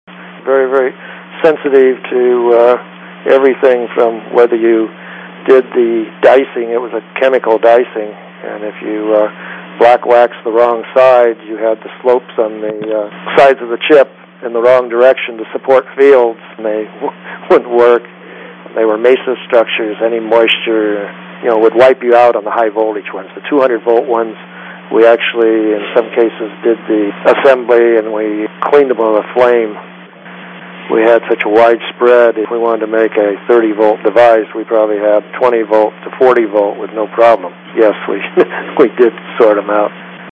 These Audio Links are Excerpts from the August 2003 Interview